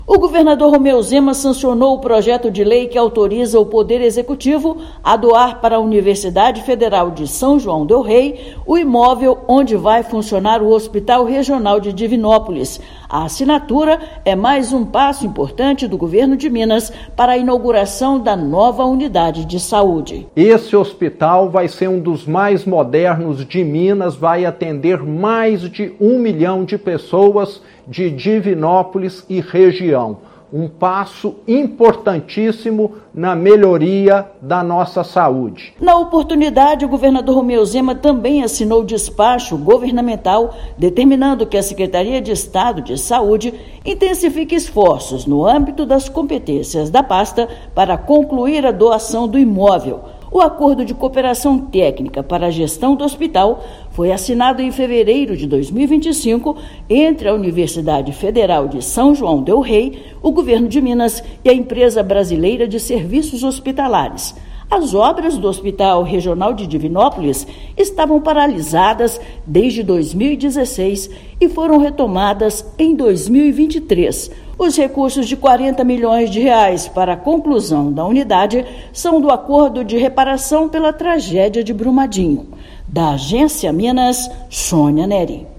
[RÁDIO] Governador sanciona lei que permite doação do Hospital Regional de Divinópolis e dá mais um passo para entrega histórica
Chefe do Executivo assinou, ainda, despacho governamental solicitando que a SES-MG tome as providências para a conclusão do processo. Ouça matéria de rádio.